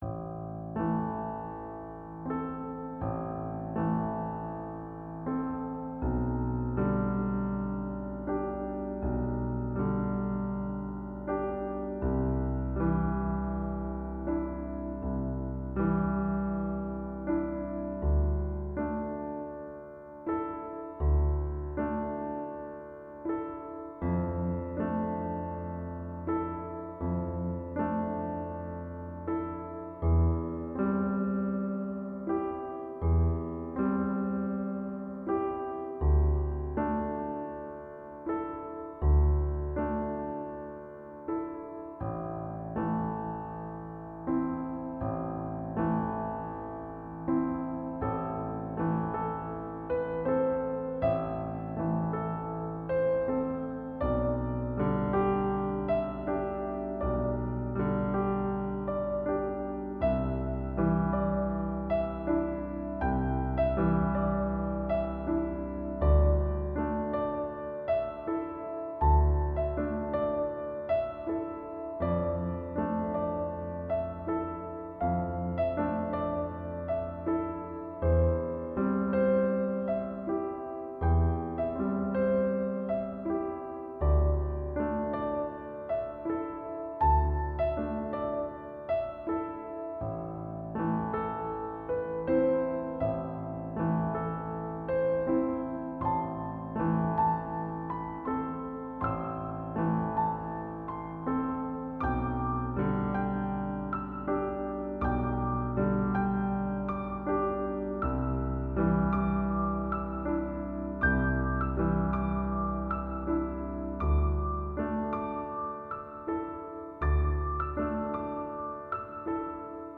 Genre Classical Modern